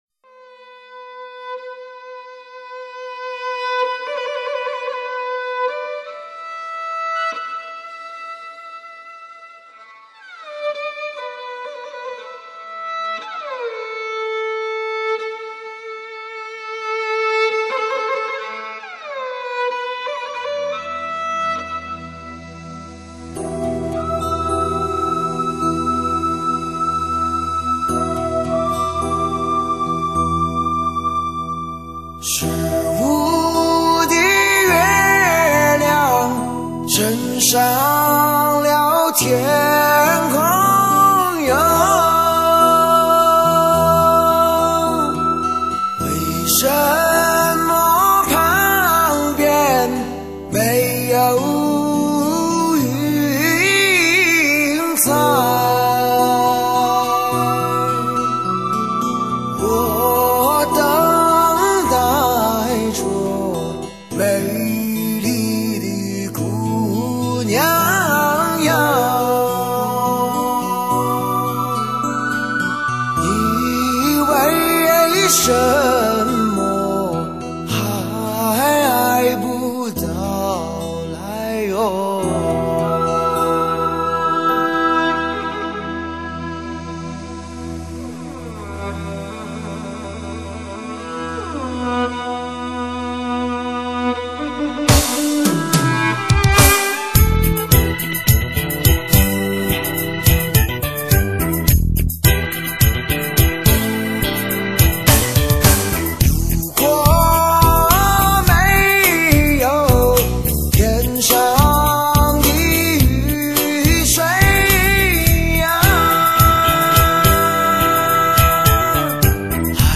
都是沙哑中带着清亮
将新疆民乐融于各种流行元素中，既有浓郁的新疆风格，又有流畅优美的旋律。